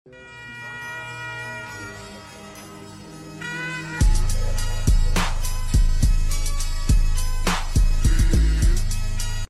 Techart MAGNUM European premiere car sound effects free download
Techart MAGNUM European premiere car in Switzerland